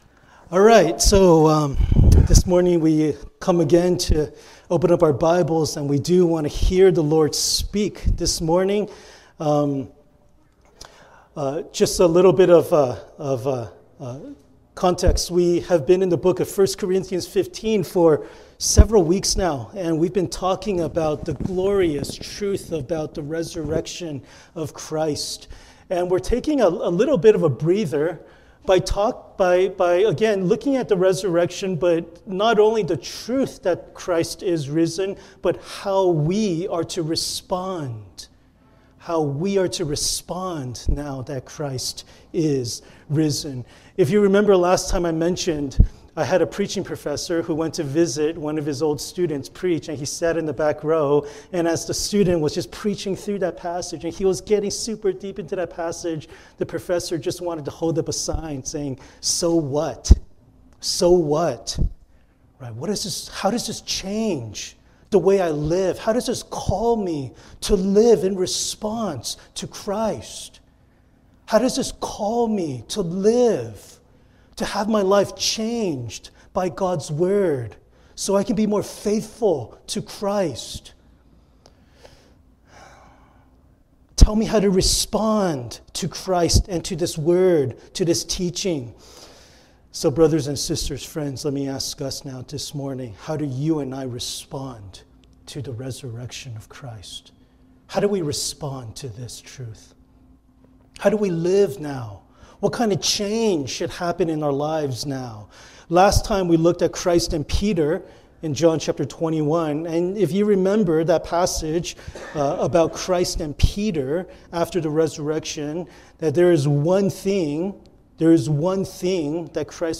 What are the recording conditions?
Passage: John 20:24-29 Service Type: Sunday Worship